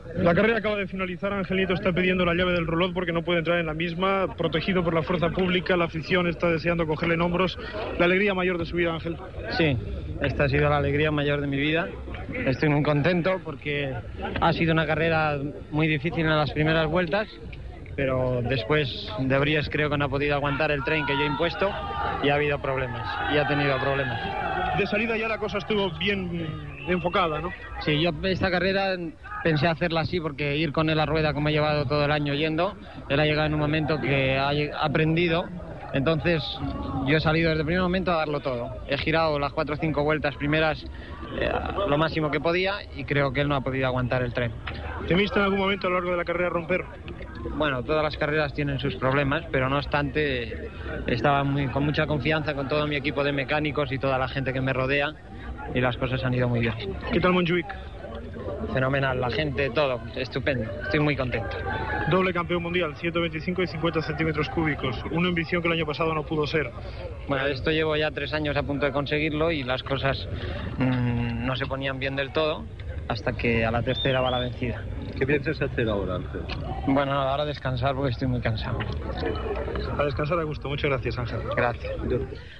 Entrevista al pilot de motociclisme Ángel Nieto, en acabar la cursa al circuit de Montjuïc (Barcelona), quan es proclama doble campió del món de motociclisme de les categories de 50cc i 125cc, amb l'escuderia Derbi
Esportiu